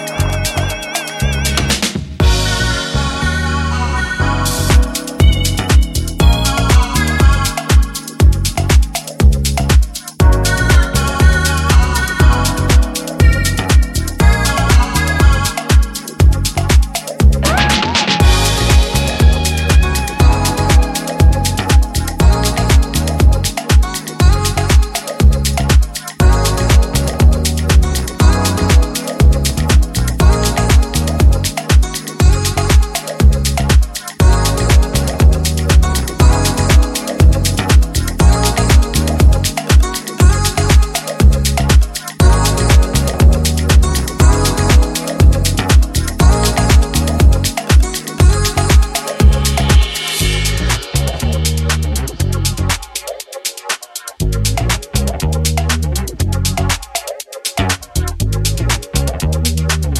ジャンル(スタイル) HOUSE